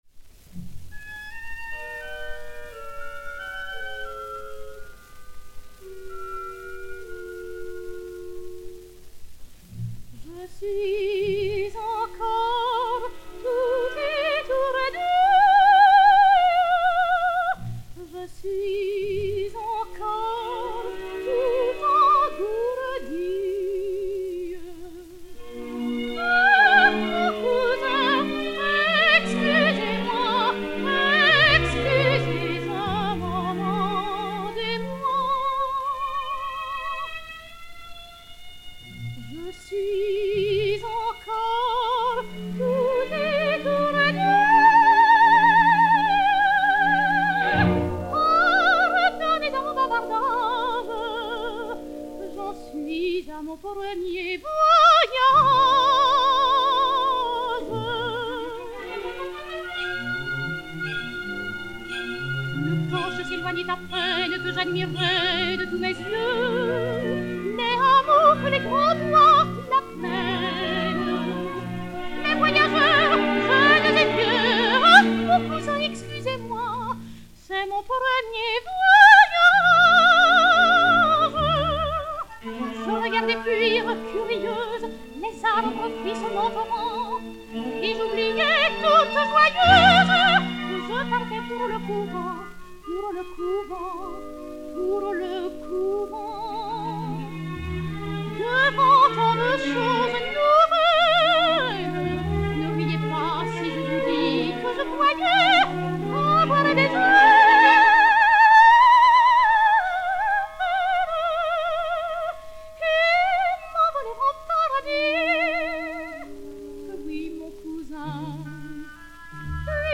CPT 6661, enr. au Théâtre des Champs-Elysées le 26 janvier 1948